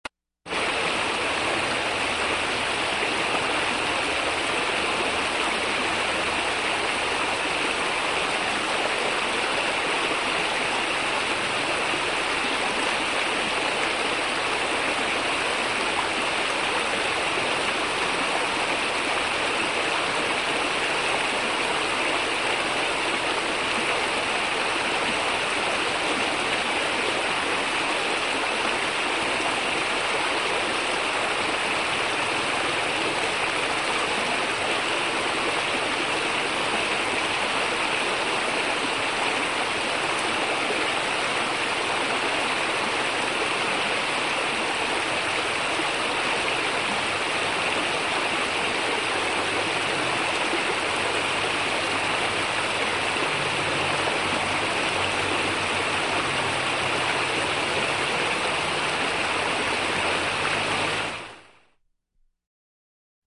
Звук река Вода
Шумная река
Shumnaia_reka.mp3